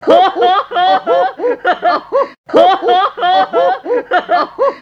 The priceless, harassing laughter of the Ashlad’s older brothers.
The sound clip is taken from the legendary stop-motion animated short by Ivo Caprino, which is based on the Norwegian folk tale “Askeladden og De Gode Hjelperne” (English: “The Ashlad and His Good Helpers”) [ref. 02:43].